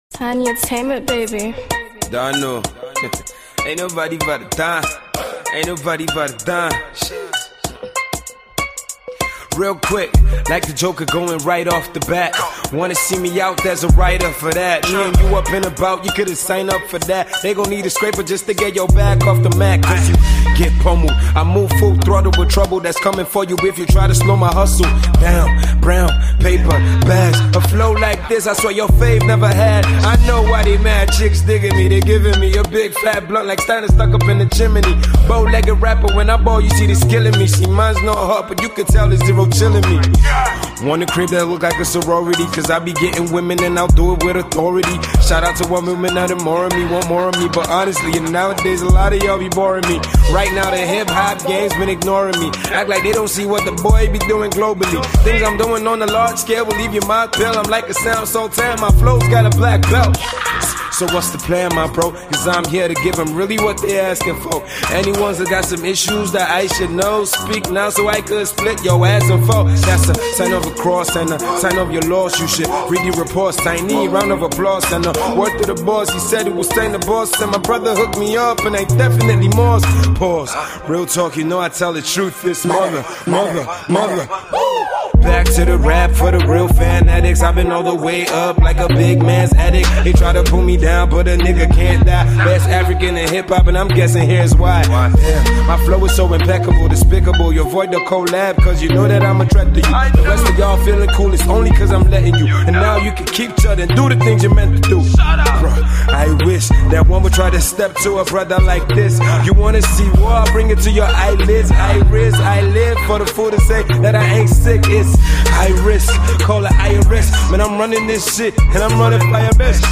AudioRap